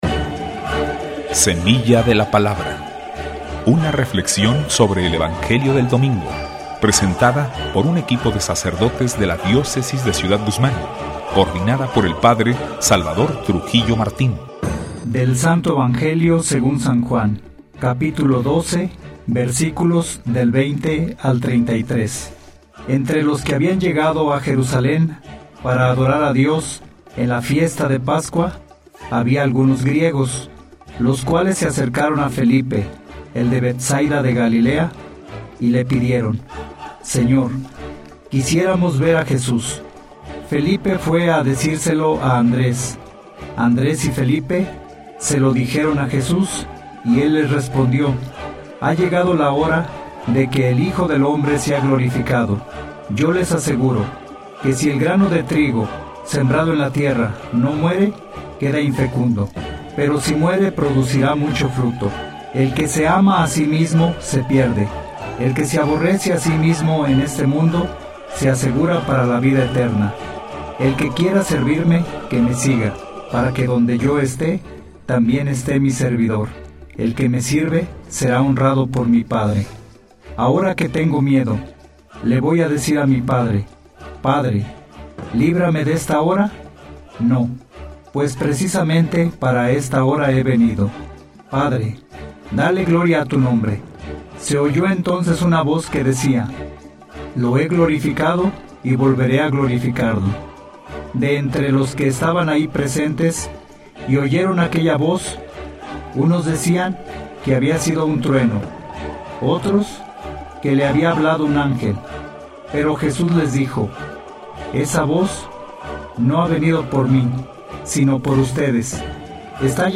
Les compartimos la reflexión dominical de La Semilla de la Palabra, transmitida durante el domingo 25 de marzo en Radio Sensación (96.7 de FM), radiodifusora de Cd. Guzmán que, desde hace tiempo, ha estado sirviendo a la difusión de las reflexiones dominicales.